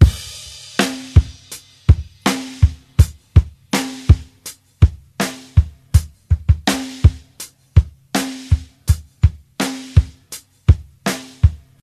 82 Bpm Old School Drum Loop Sample F Key.wav
Free drum groove - kick tuned to the F note. Loudest frequency: 788Hz
82-bpm-old-school-drum-loop-sample-f-key-E6a.ogg